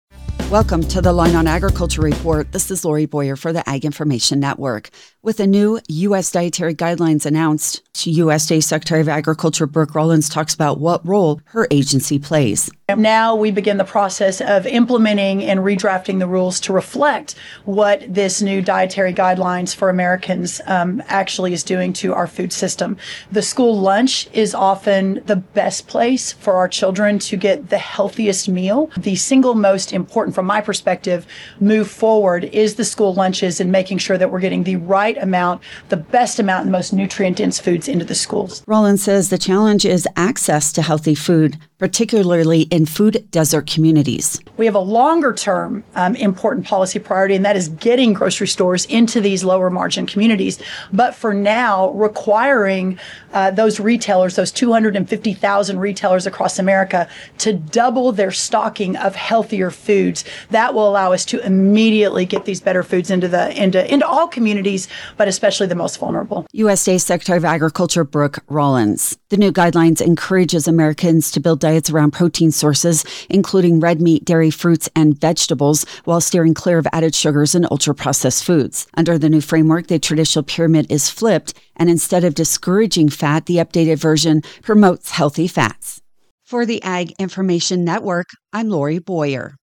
Reporter
With the new US dietary guidelines announced, USDA Secretary of Agriculture, Brooke Rollins, talks about what role her agency plays.